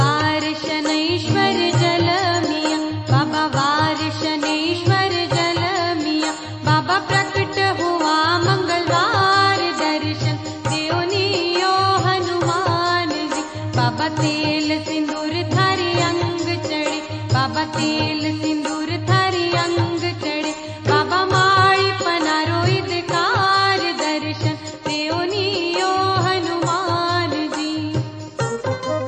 CategoryDevotional Ringtones